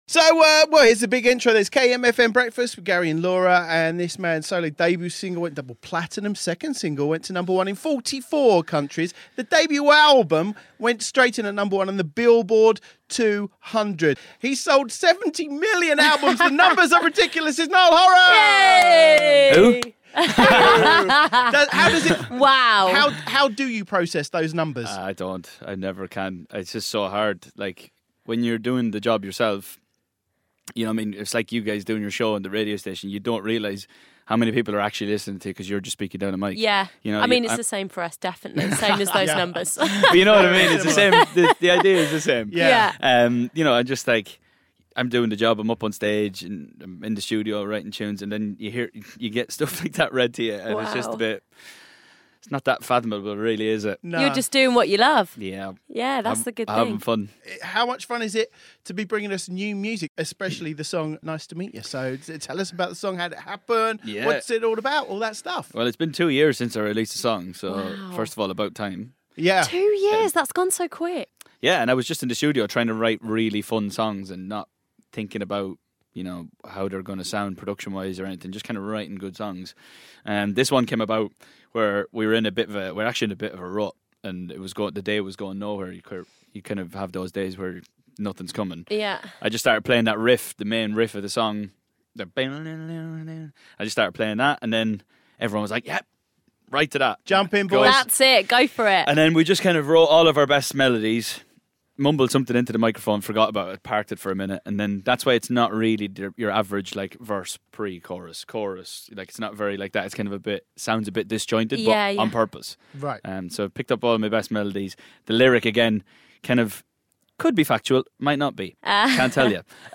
Niall Horan has been telling us all about his new single 'Nice To Meet Ya' and playing Celebrity Say It on kmfm Breakfast